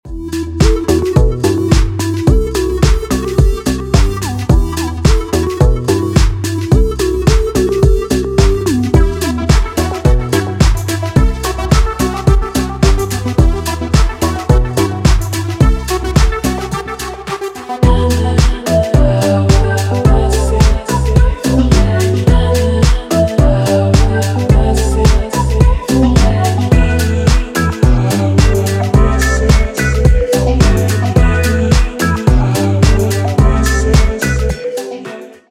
атмосферные
спокойные
Стиль: deep house